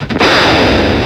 Cri de Crocorible dans Pokémon X et Y.